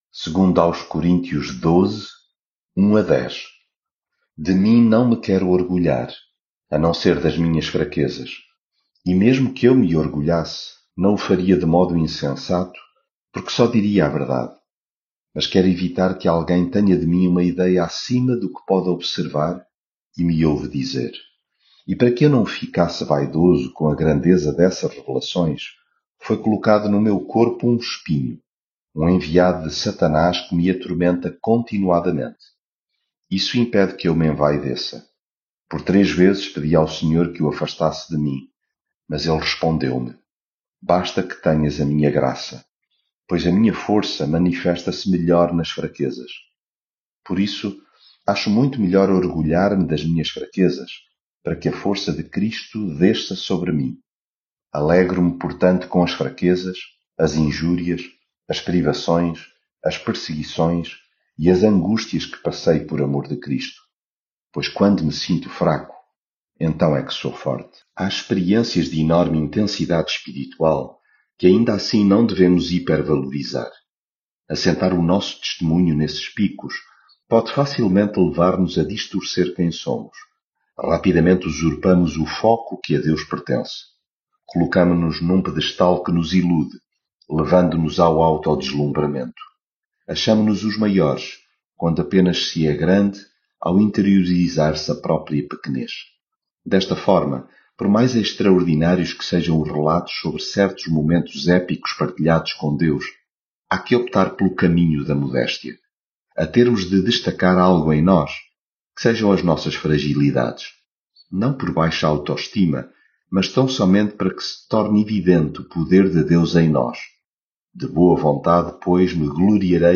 devocional coríntios leitura bíblica Embora não seja conveniente alguém gloriar-se é necessário que o faça para falar das visões e revelações que recebi do Senhor....